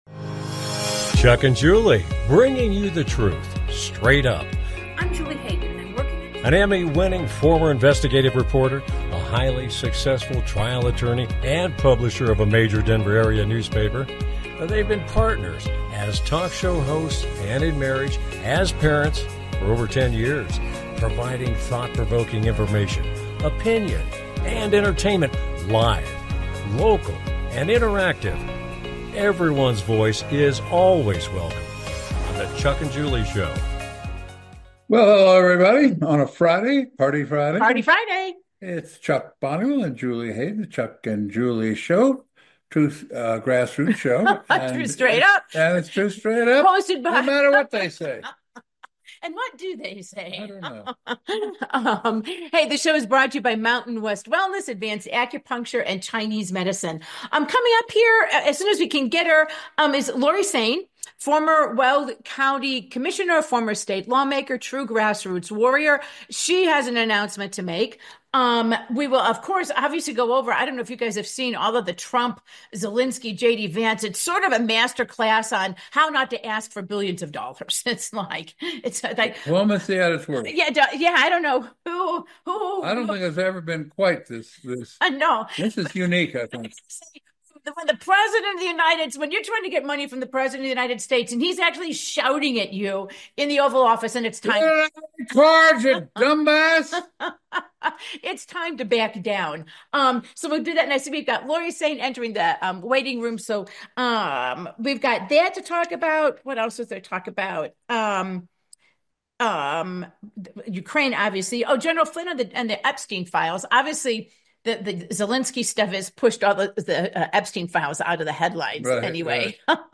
Talk Show Episode
With Guest, Lori Saine - Grassroots warrior Lori Saine announces her bid for the Colorado Republican Party Chair